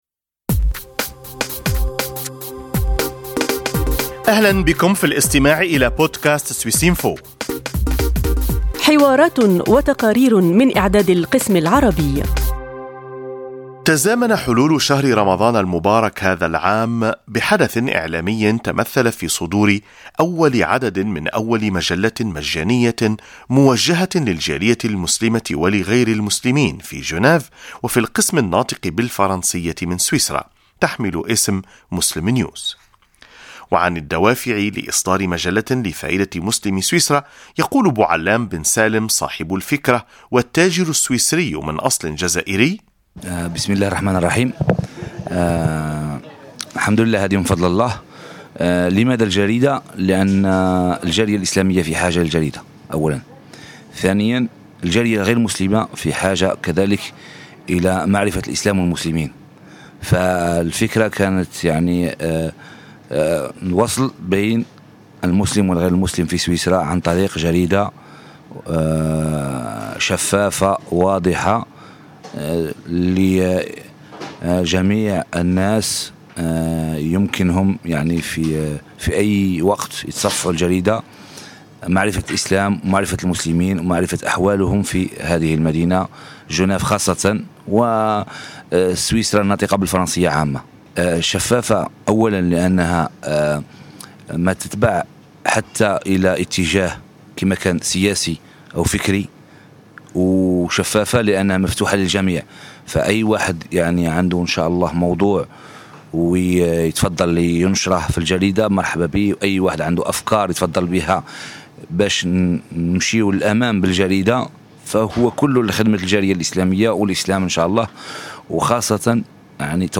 تزامن حلول شهر رمضان مع صدور العدد الأول من أول مجلة مجانية موجهة للجالية المسلمة ولغير المسلمين في جنيف وغرب سويسرا. حديث